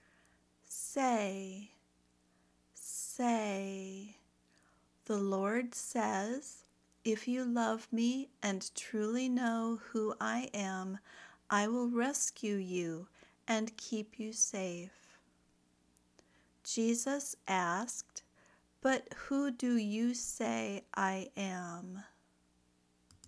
/seɪ/ (verb)